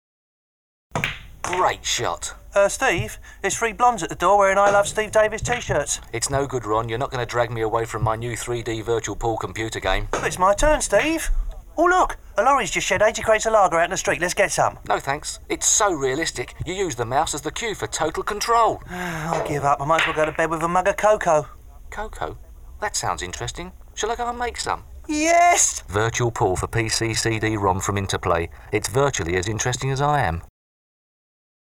Virtual Pool Radio Ad
Virtual_Pool_radio.mp3